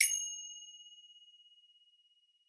finger_cymbals_crash02
bell chime cymbal ding finger-cymbals orchestral percussion sound effect free sound royalty free Sound Effects